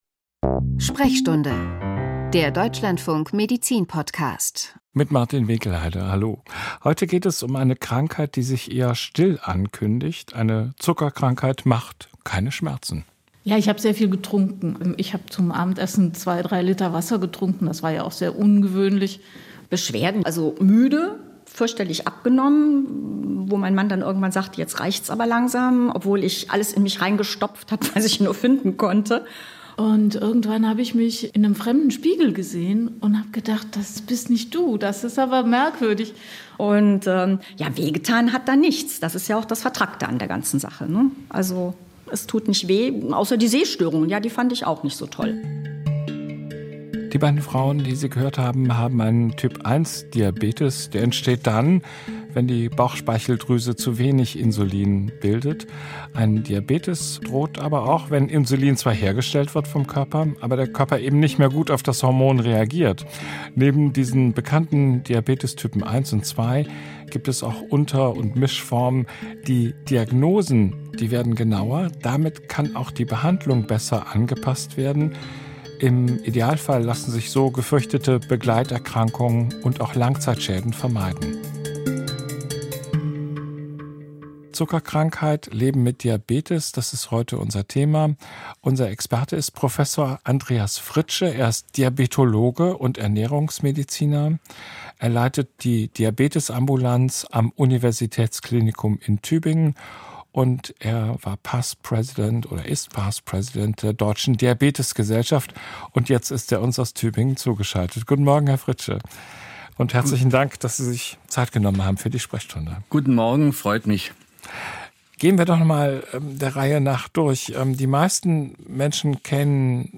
Ein Experte gibt Auskunft über den Erkenntnisstand seines medizinischen Fachgebietes und beantwortet anschließend Hörerfragen; die Sendung wird ergänzt durch einen aktuellen Info-Block.